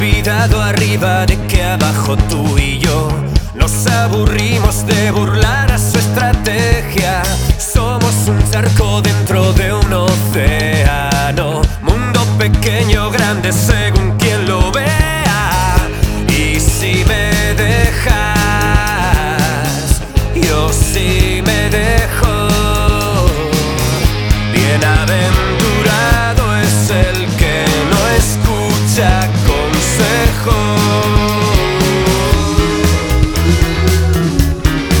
Жанр: Альтернатива
# Adult Alternative